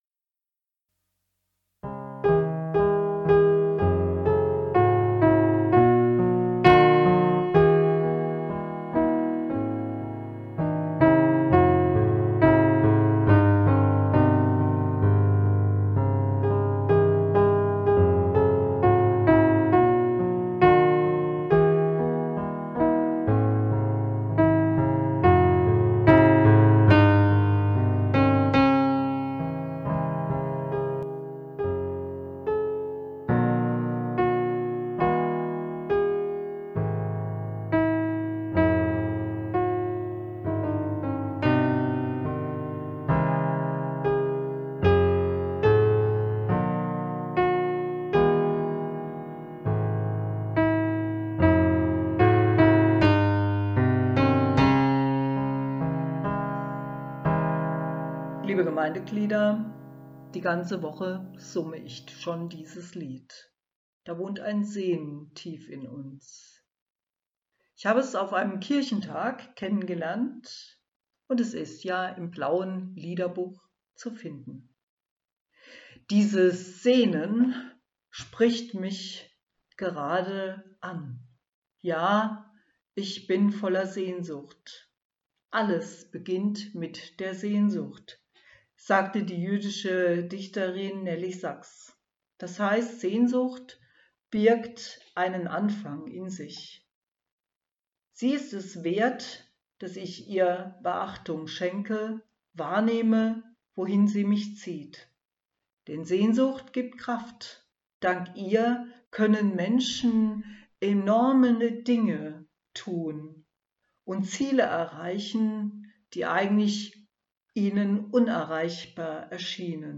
Hier die mp3 Dateien eine Kurzandacht zum Thema "Da wohnt ein Sehnen"
Da-wohnt-ein-Sehnen--Piano-Ansprache-Piano-Ansprache.mp3